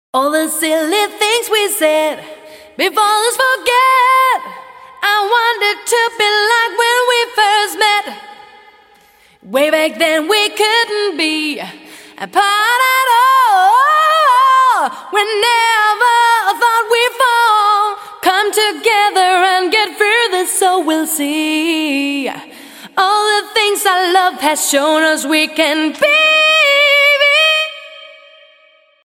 ensuite la même voix avec une grosse dose de reverbe (une plate assez longue); admettez que c'est envahissant !!
=> la voix réverbérée
vox_rev1.mp3